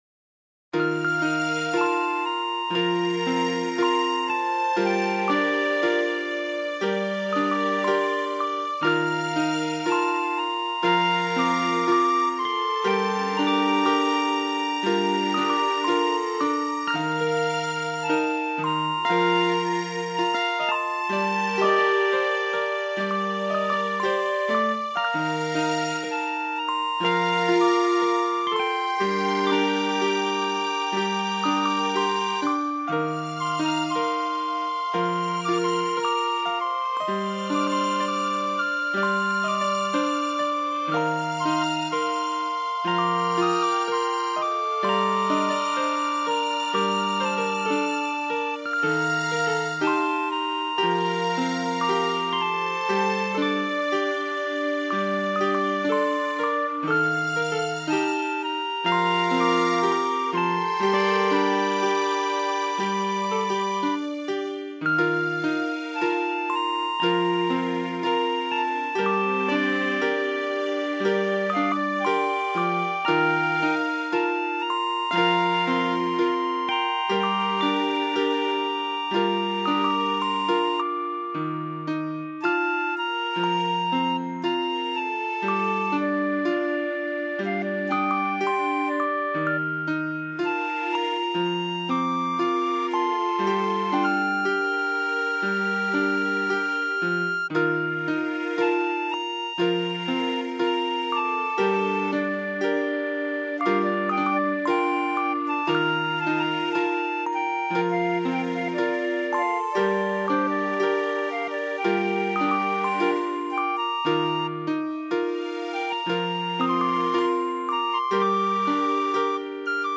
Something softer for a change